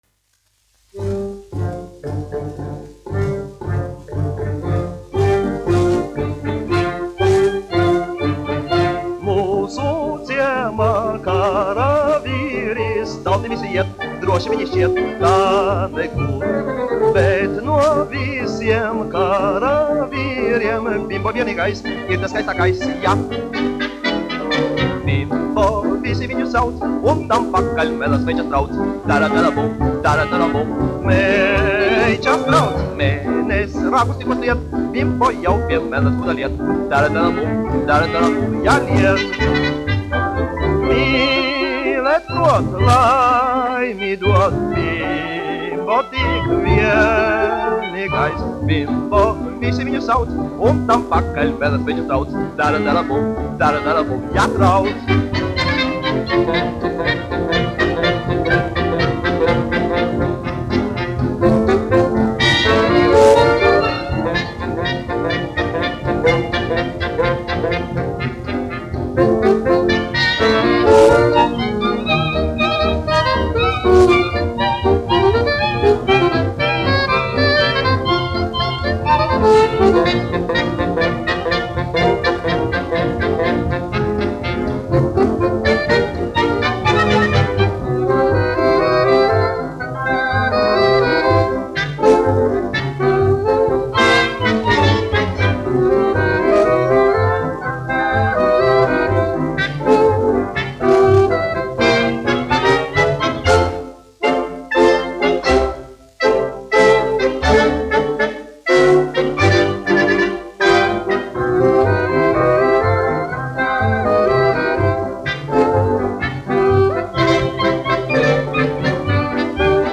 1 skpl. : analogs, 78 apgr/min, mono ; 25 cm
Fokstroti
Latvijas vēsturiskie šellaka skaņuplašu ieraksti (Kolekcija)